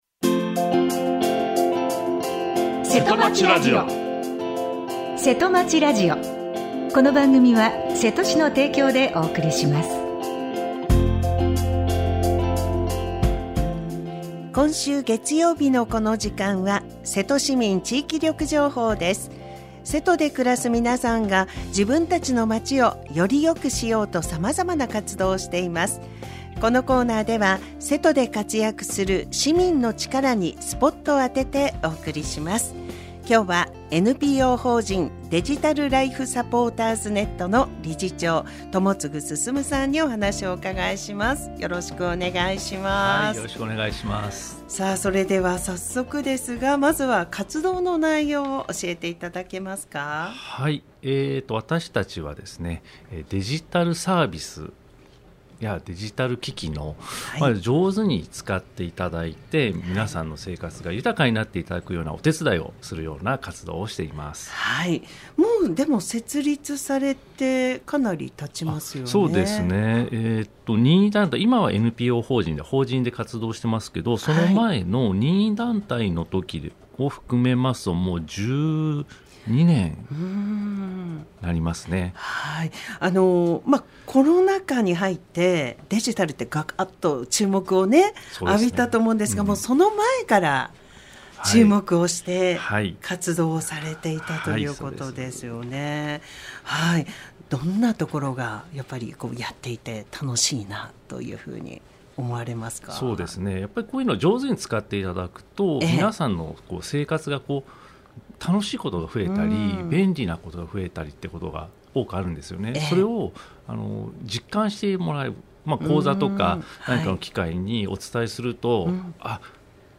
, 生放送 | radiosanq-hp | 2025年1月6日 9:30 AM | 2025年1月6日（月） は コメントを受け付けていません